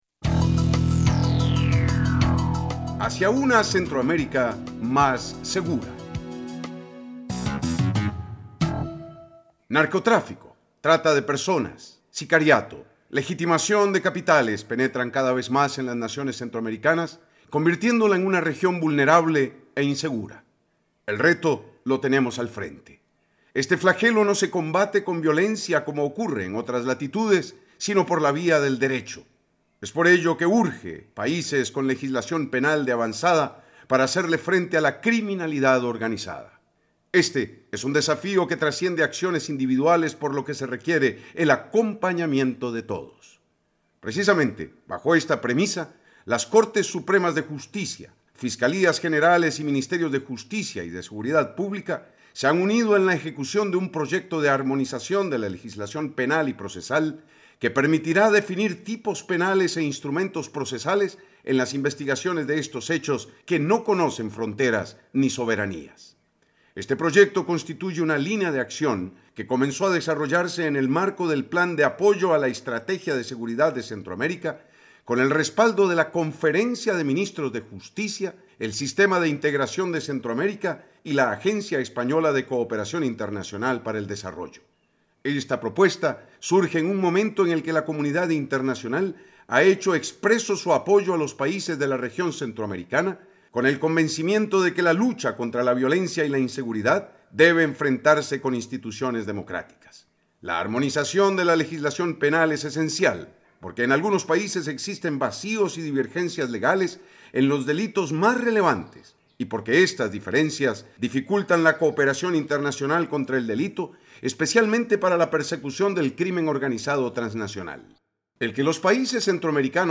Comentarista Invitado